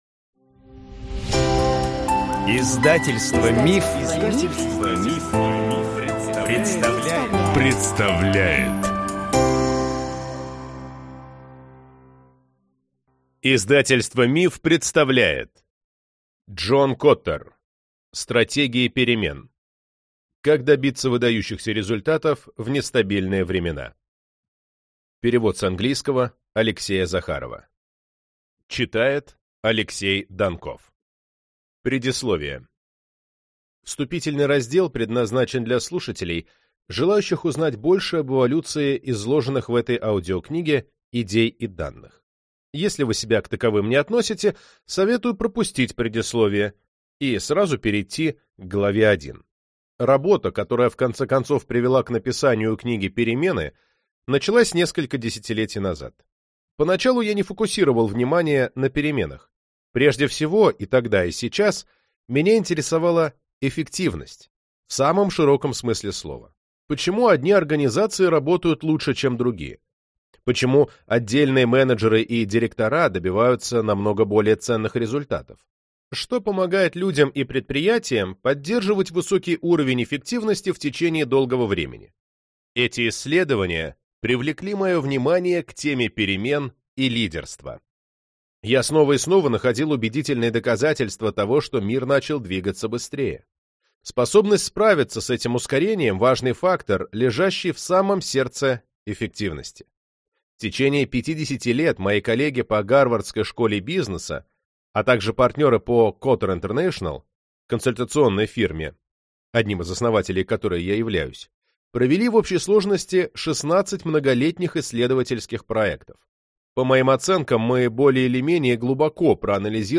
Аудиокнига Стратегии перемен. Как добиться выдающихся результатов в нестабильные времена | Библиотека аудиокниг